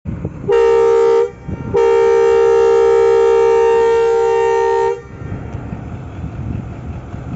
38 Dn Fareed Express Crossing Sound Effects Free Download